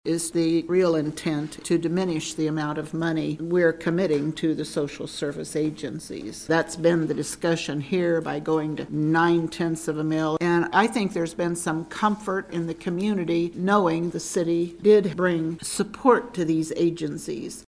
Commissioner Linda Morse says she has concerns about the commission’s intentions.